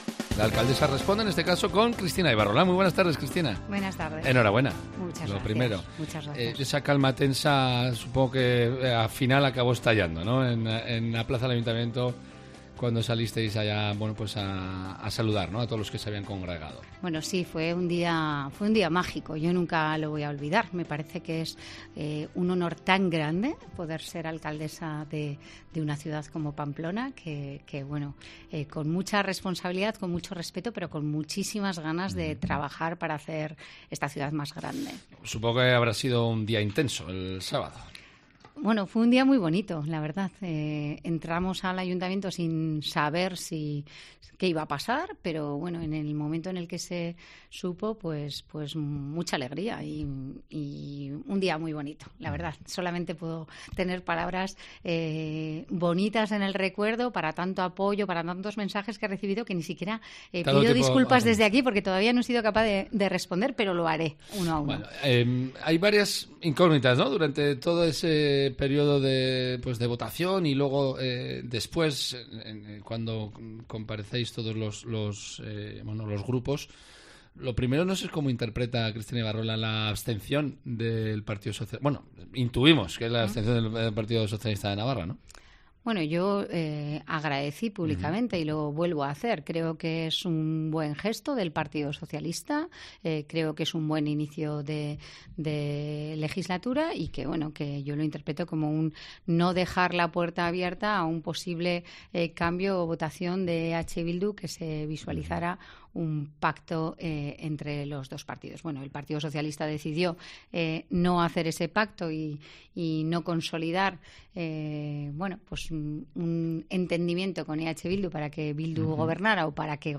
AUDIO: Cristina Ibarrola, nueva alcaldesa de Pamplona, ha estado en los micrófonos de Cope Navarra